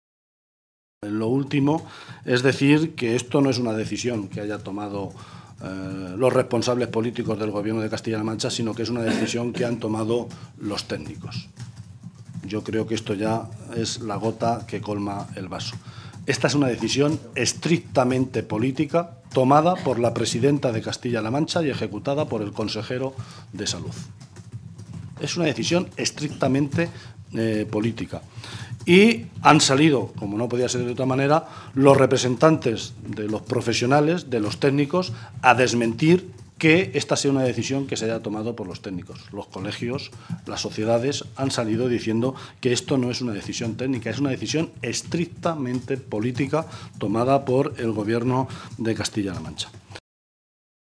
José Luís Martínez Guijarro, portavoz del Grupo Parlamentario Socialista
Cortes de audio de la rueda de prensa